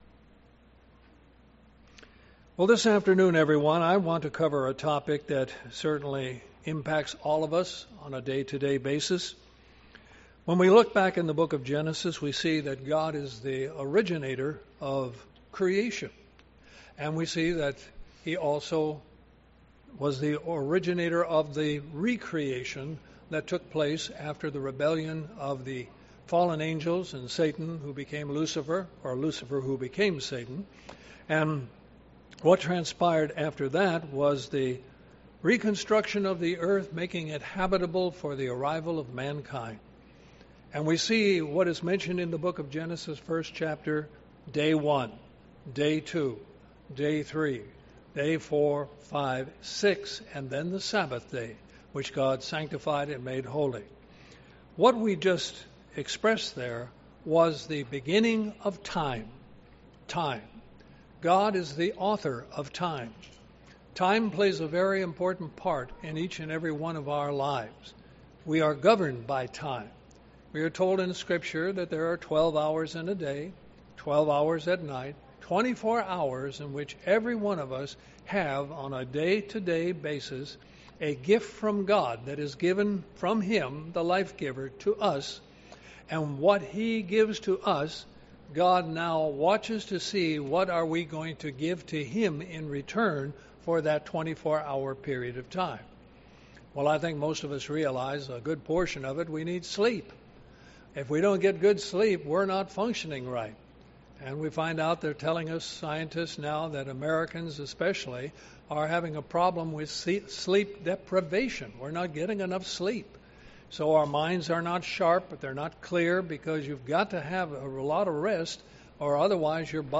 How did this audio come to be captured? Given in Columbus, GA